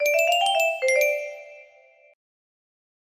The Lick music box melody